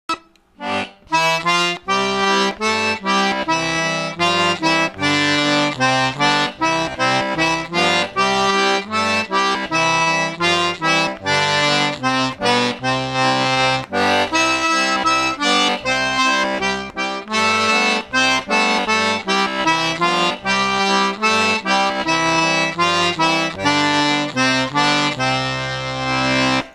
Learning to play a song on the Cajun accordion starts with the fundamental memorizing of the song.
To illustrate this method, I have chosen a Carter Family folk tune named "Wildwood Flower".
I'm starting out at the most basic level by playing one-note-at-a-time.
simplemelody.wma